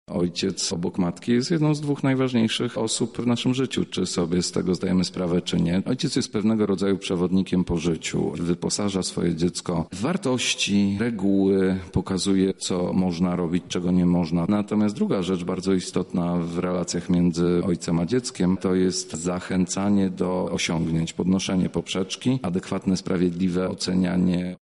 O roli ojca w rozwoju dziecka mówi psycholog